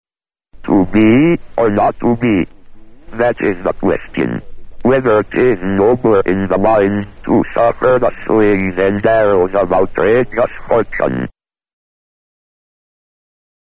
16. The first computer-based phonemic synthesis-by-rule program